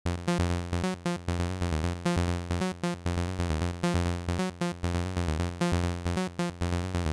Es un sonido bastante sencillo, solo necesitaremos un oscilador para hacerlo, así que desactiva el Oscilador 2 y el ‘Noise’.
Cambia los valores de la envolvente a los siguientes: ataque 5ms, ‘decay’ 800 ms, ‘sustain’ a 0 y 500 ms de ‘release’.
Las líneas de bajo Acid no tocan más de una nota a la vez, así que no necesitamos polifonía
No es muy emocionante, ¿verdad?.